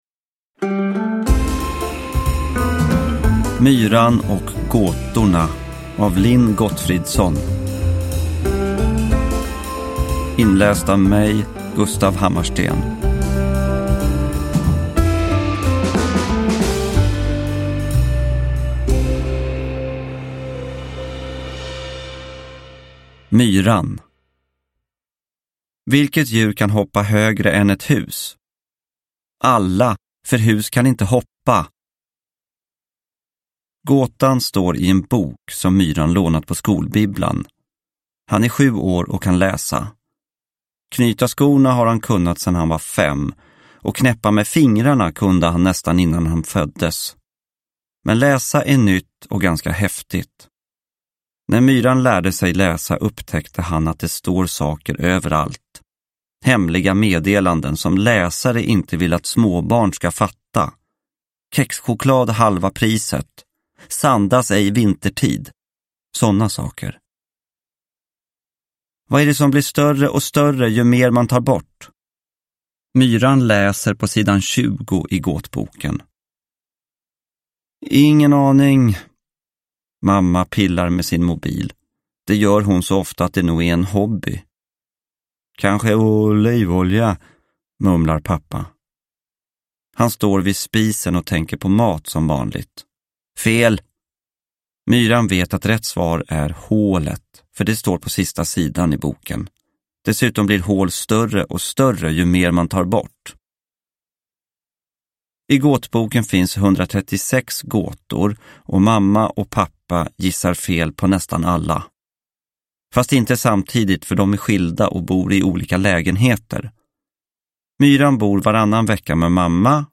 Myran och gåtorna – Ljudbok – Laddas ner
Uppläsare: Gustaf Hammarsten